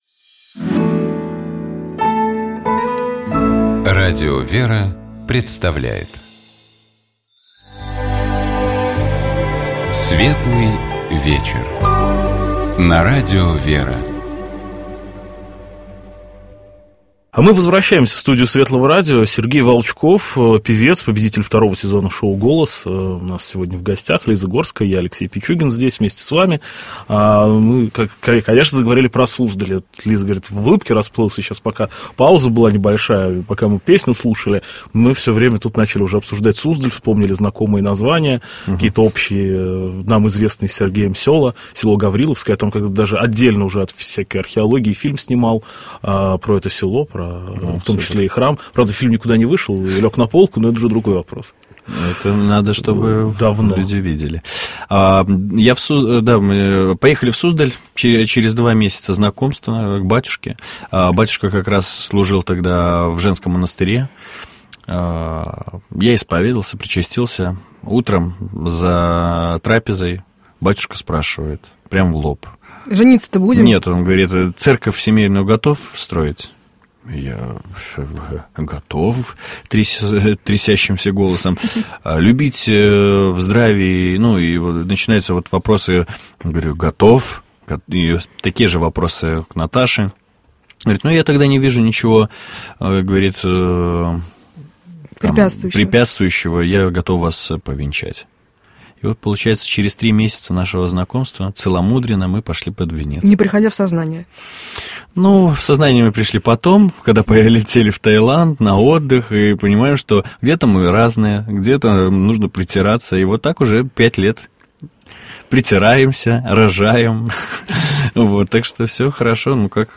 У нас в гостях был певец Сергей Волчков.